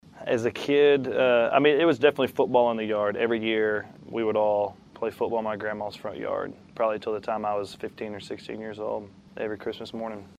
Audio / / PARKER MCCOLLUM TALKS ABOUT HIS FAVORITE CHRISTMAS CHILDHOOD MEMORY.